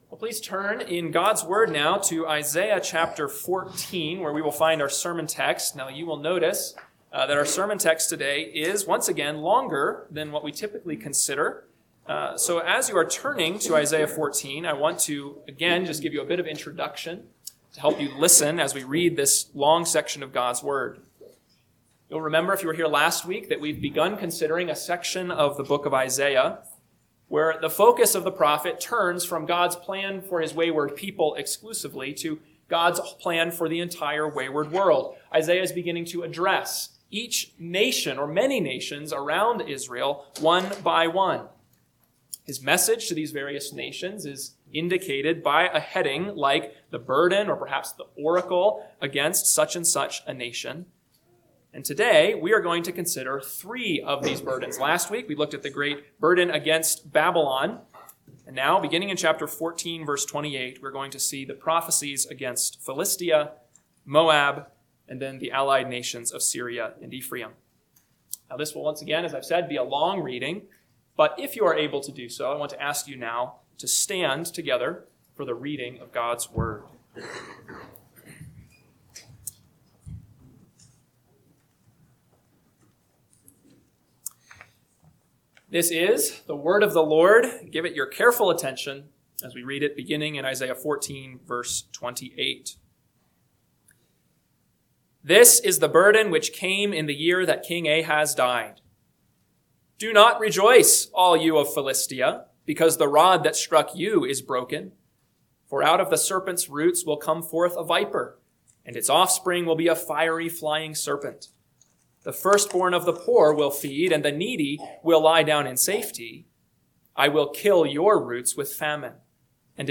AM Sermon – 3/1/2026 – Isaiah 14:28-18:7 – Northwoods Sermons